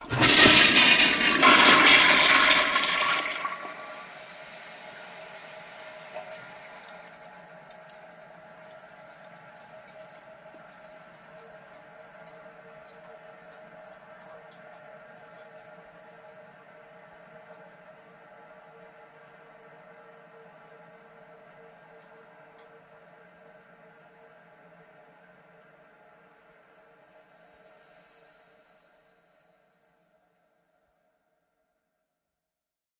На этой странице собраны звуки, характерные для общественных туалетов: журчание воды, работа сантехники, эхо шагов по кафелю и другие бытовые шумы.
Шум слива воды в туалете на судне или яхте